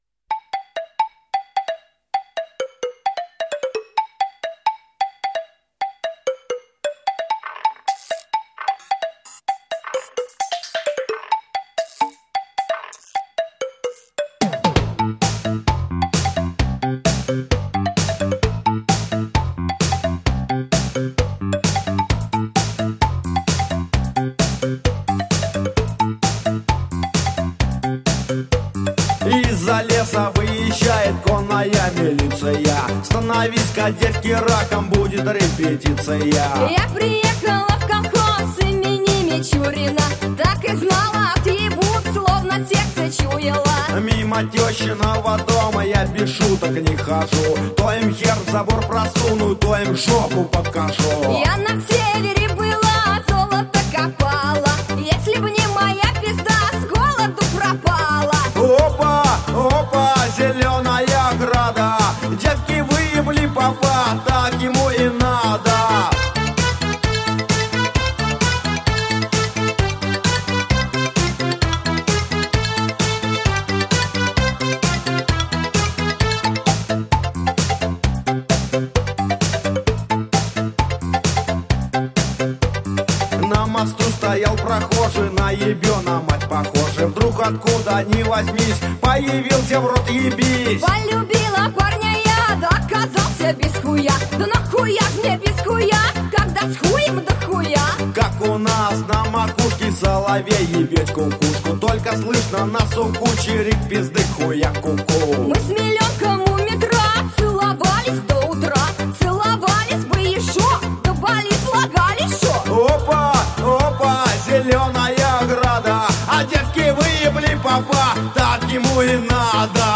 12_Chastushki.mp3